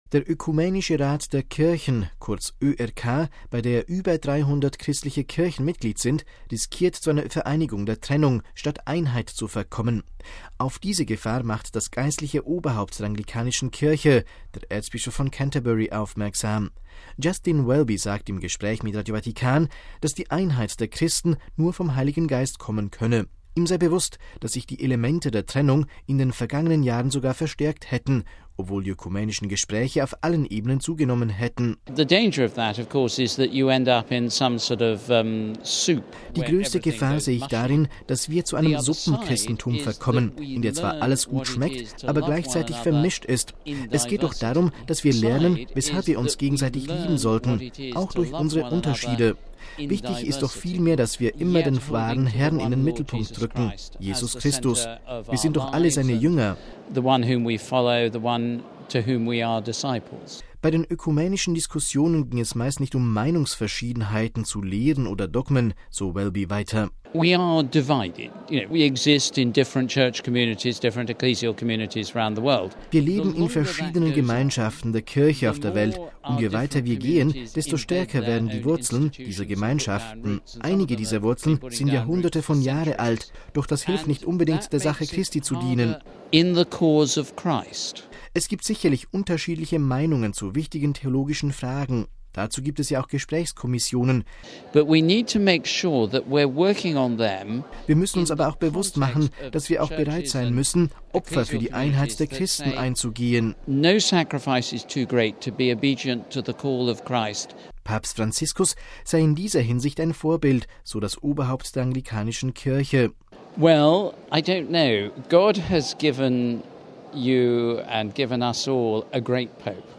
Auf diese Gefahr macht das geistliche Oberhaupt der anglikanischen Kirche, der Erzbischof von Canterbury, aufmerksam. Justin Welby sagte im Gespräch mit Radio Vatikan, dass die Einheit der Christen nur vom Heiligen Geist kommen könne.